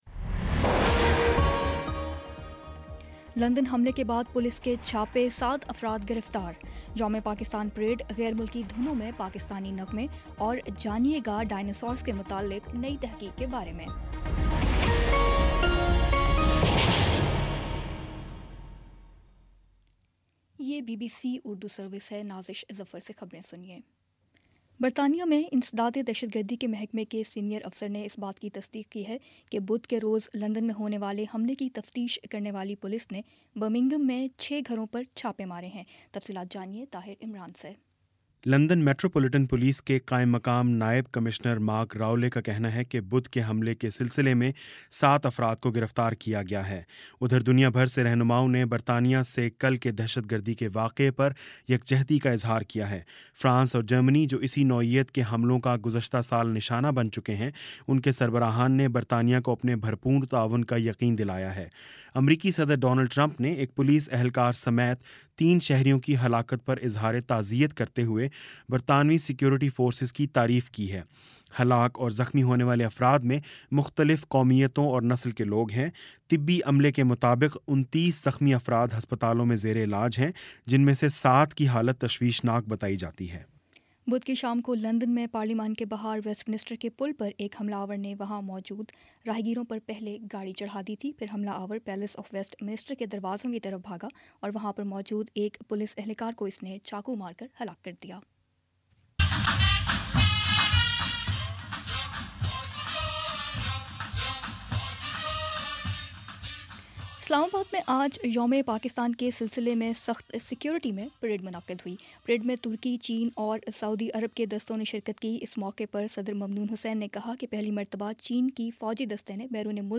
مارچ 23 : شام پانچ بجے کا نیوز بُلیٹن